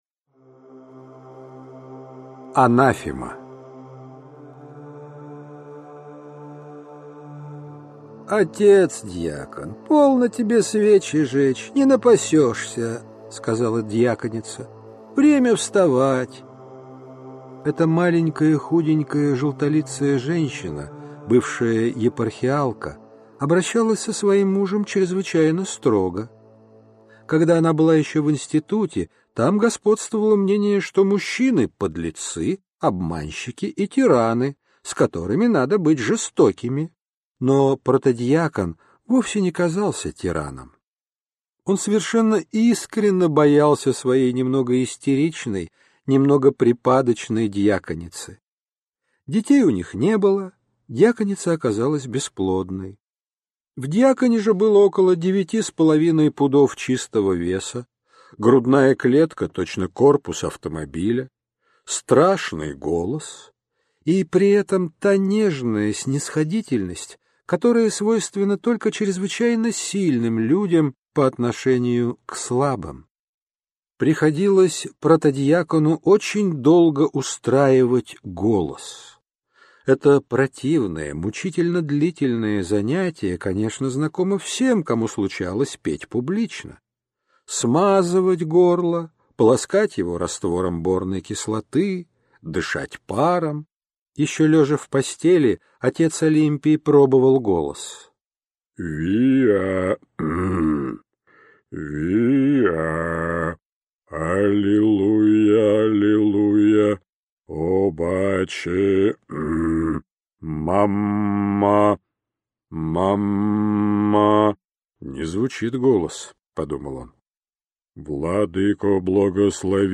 Аудиокнига Гамбринус, Анафема | Библиотека аудиокниг